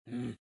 描述：Voz de personaje坟墓，没有领带palabras，独唱儿子sonidos de rabia。